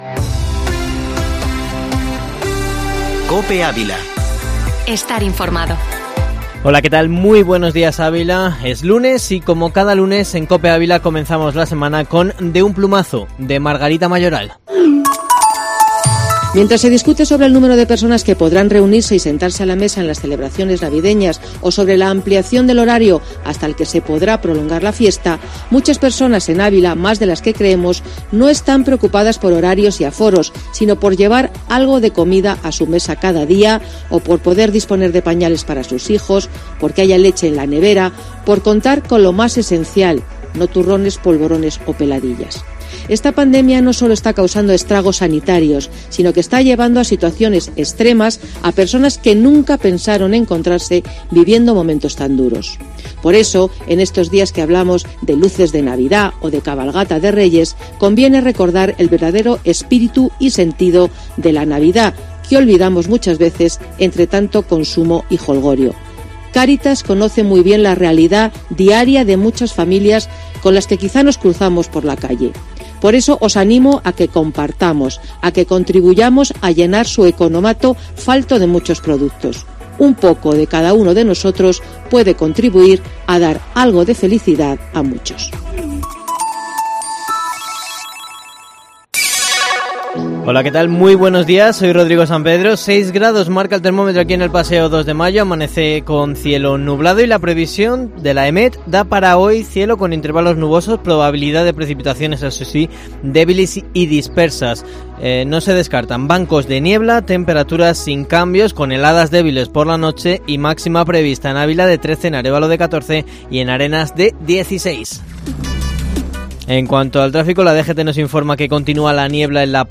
Informativo matinal Herrera en COPE Ávila 30/11/2020